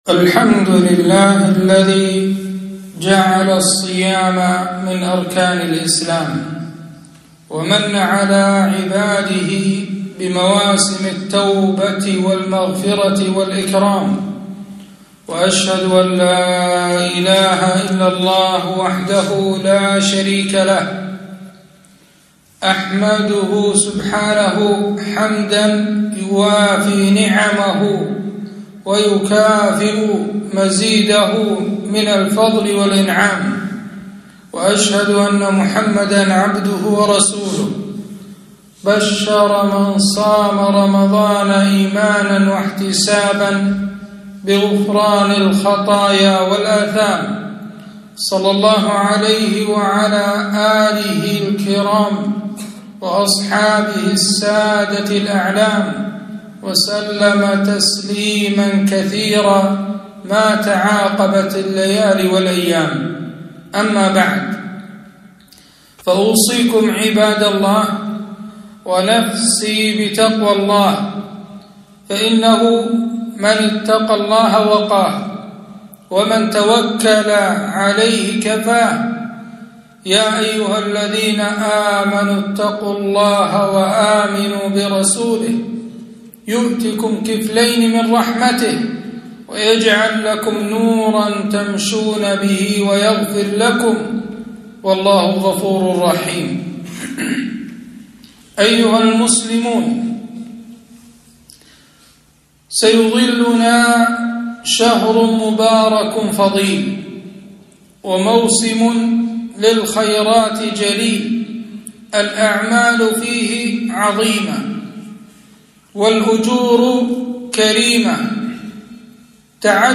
خطبة - أتاكم رمضان شهر مبارك